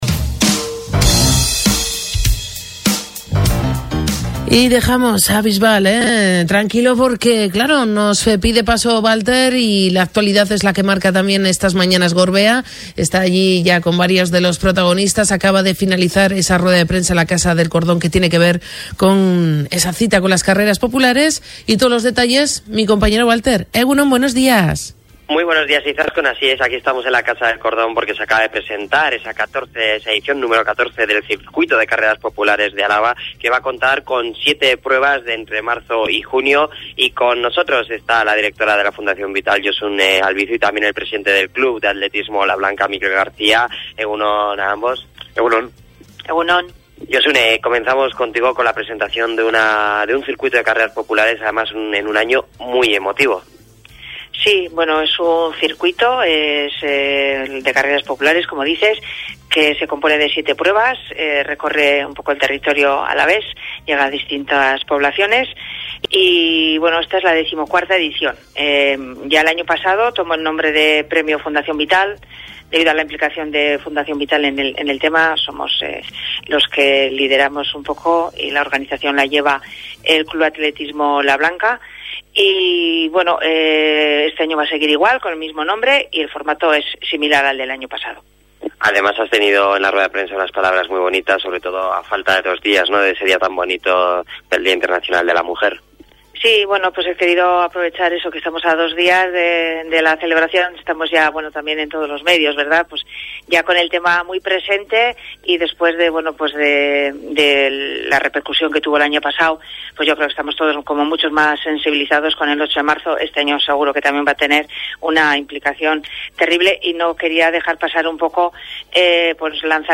Entrevista-Fundacion-Vital.mp3